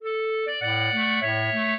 clarinet
minuet14-12.wav